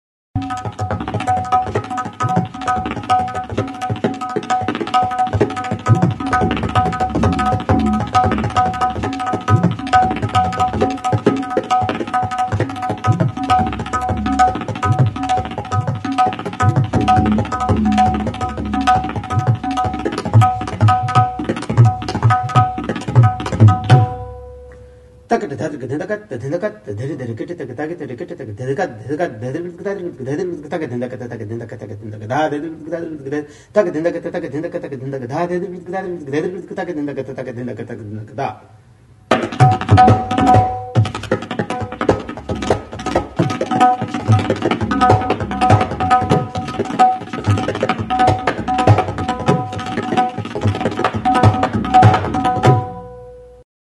Membranophones -> Beaten -> Hand-beaten drums
Baya eta Tabla danbor bikotea elkarrekin jotzen dira.
Metalezko kaxa duen tinbal moduko danborra da.